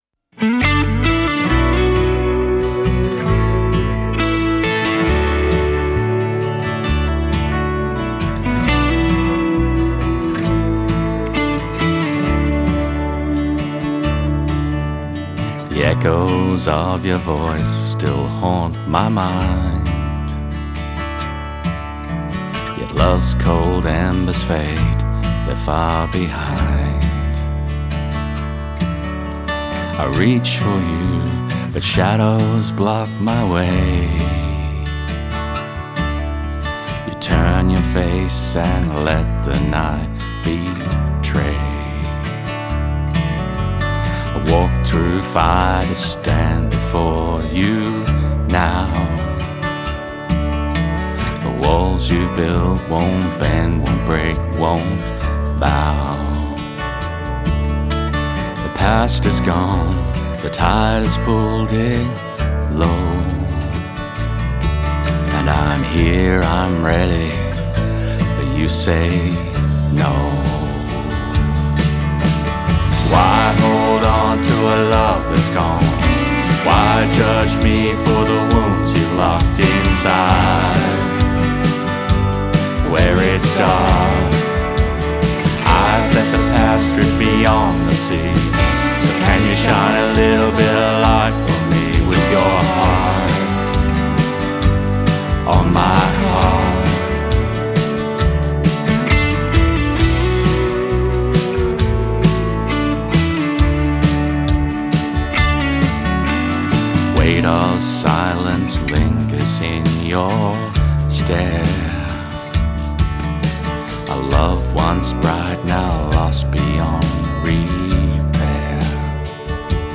heartfelt country single
lived-in storytelling paired with melodies that linger.